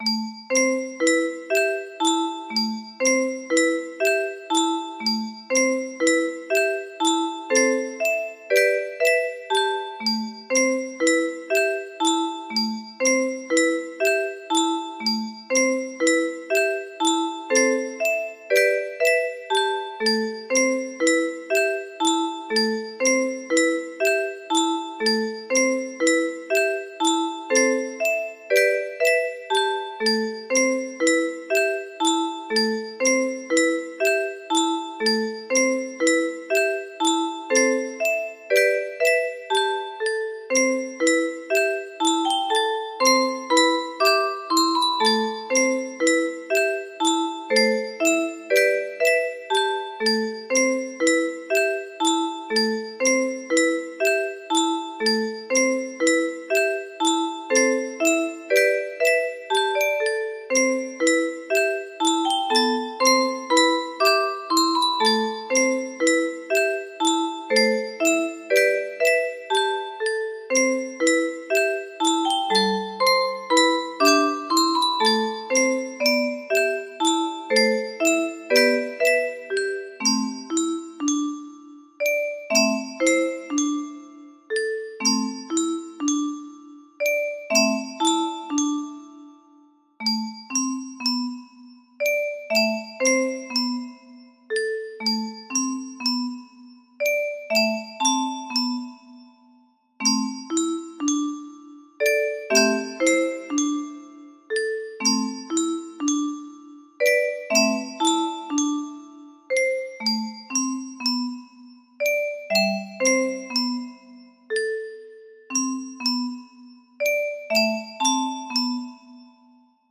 version for a 30 note musicbox